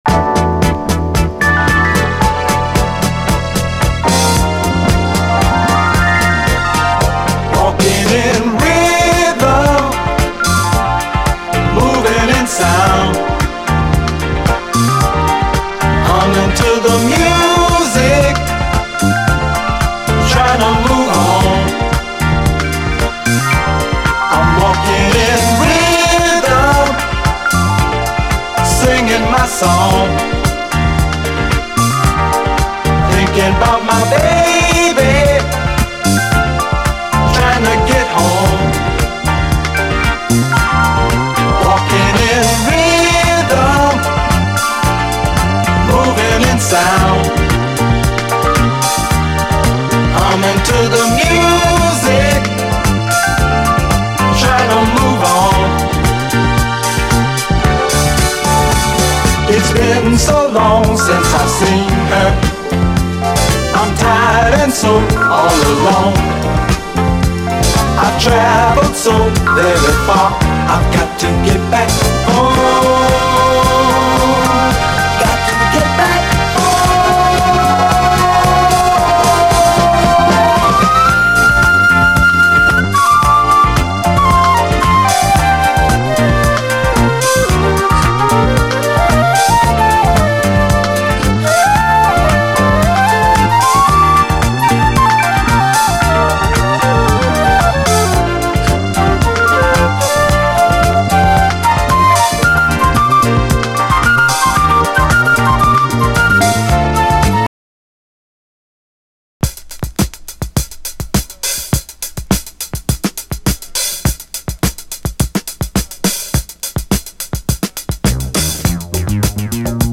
SOUL, 70's～ SOUL, DISCO, 7INCH
レアグルーヴ・クラシックとして知られるメロウ・ステッパー！